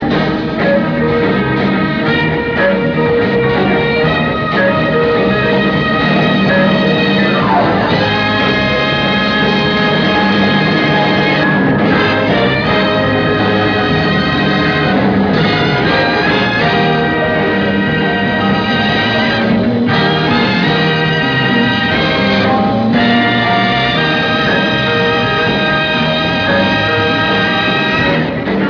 Non-Album songs that are known to have been played live: